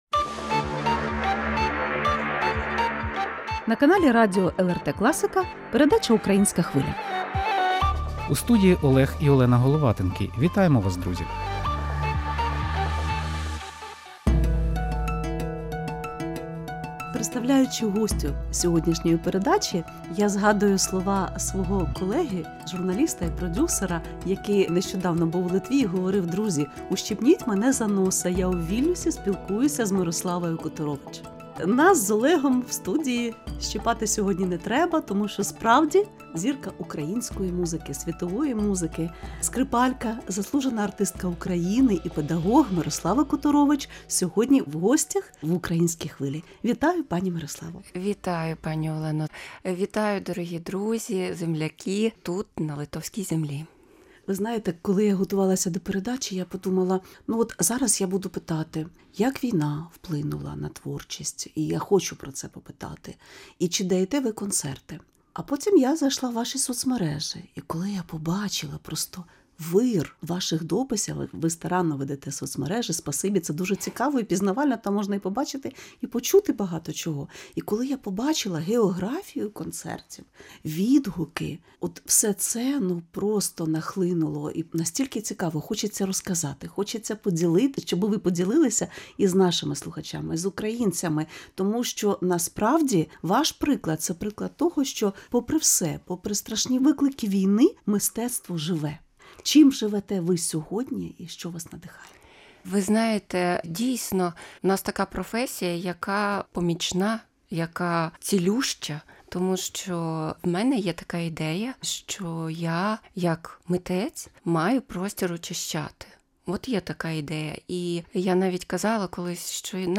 Скрипка, що озивається світлом. Інтерв'ю з Мирославою Которович